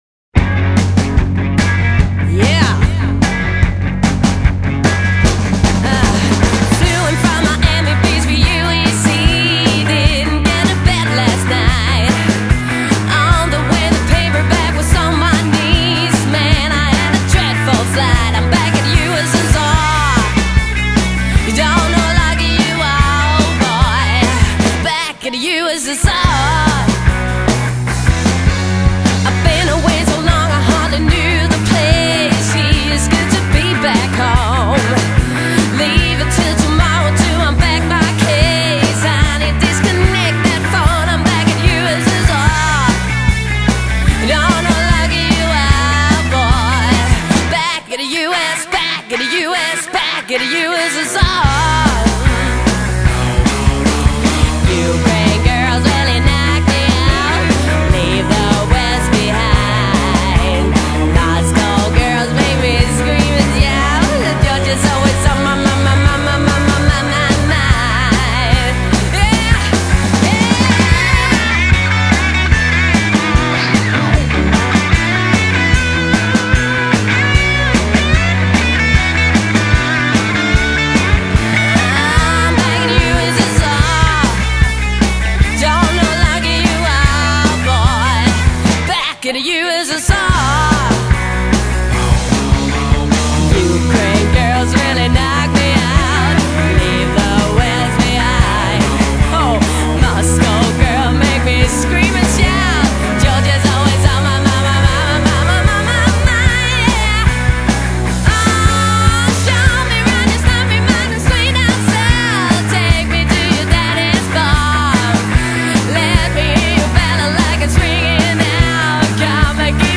Det vil sige, bas, trommer, og nogle kor stemmer.